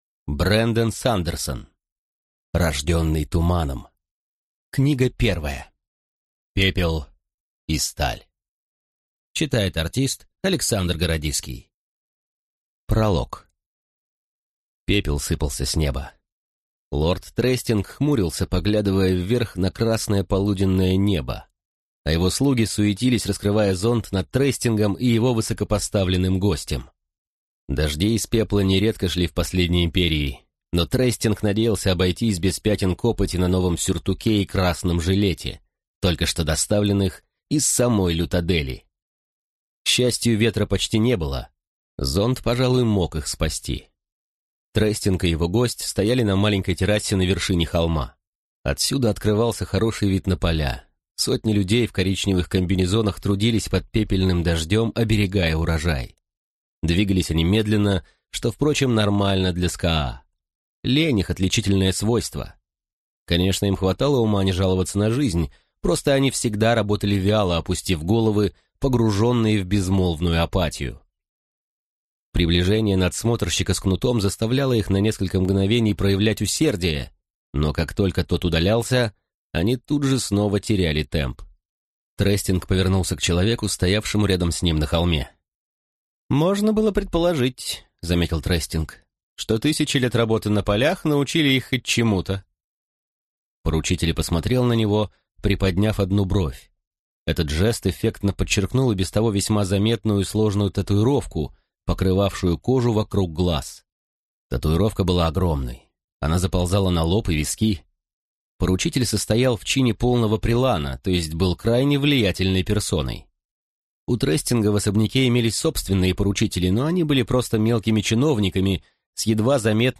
Аудиокнига Пепел и сталь | Библиотека аудиокниг